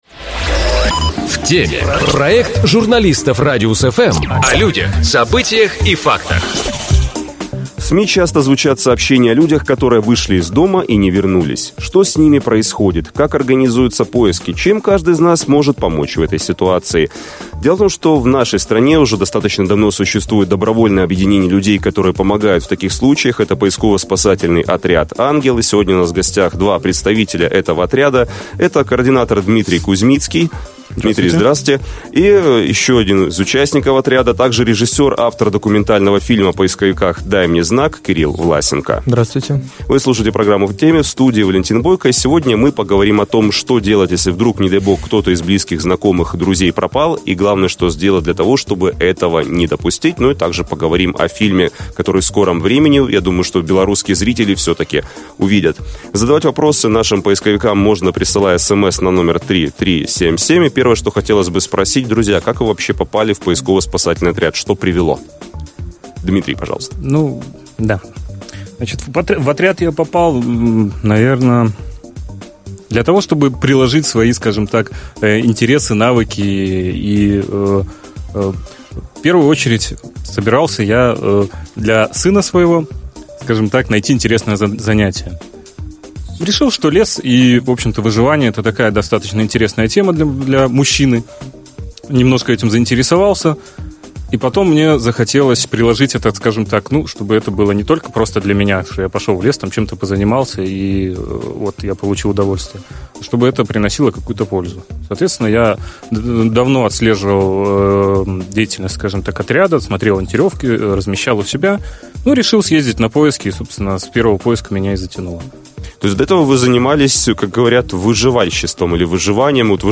Мы говорим с участниками поисково-спасательного отряда "Ангел"